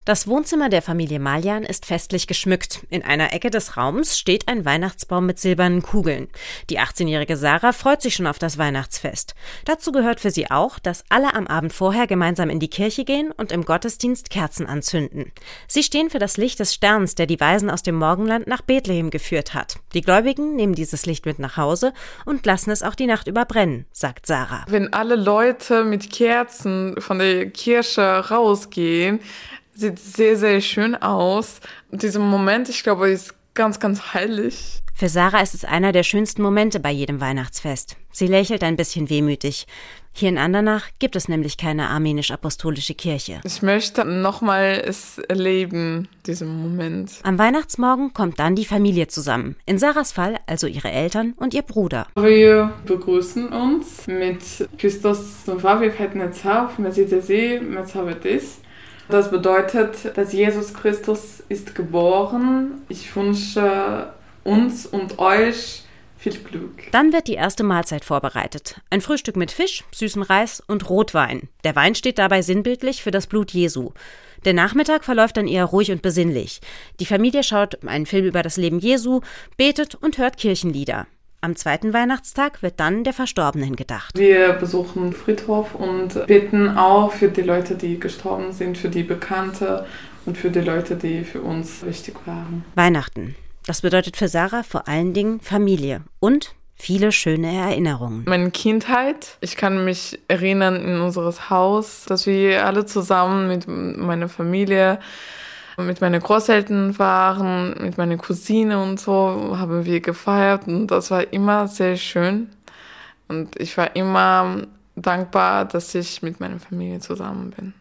Eine armenische Familie aus Andernach erzählt wie sie Weihnachten am 6. Januar feiert.